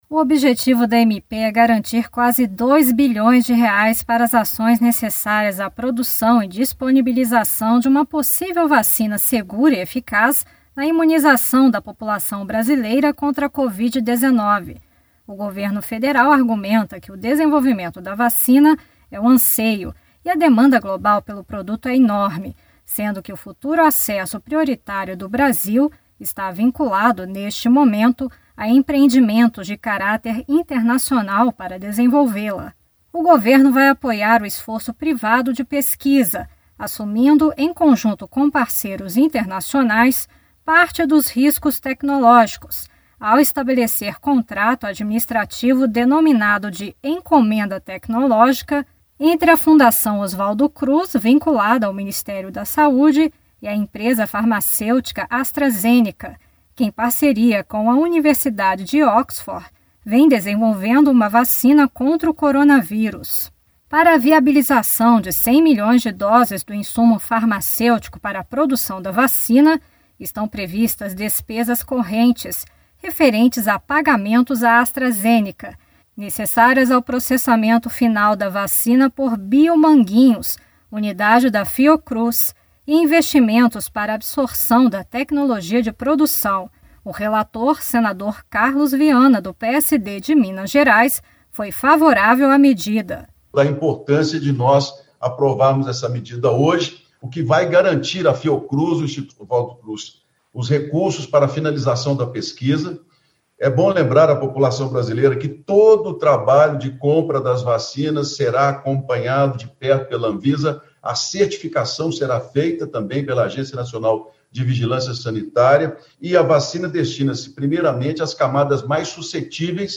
Quase R$ 2 bilhões serão destinados para o Ministério da Saúde viabilizar a fabricação de 100 milhões de doses. Reportagem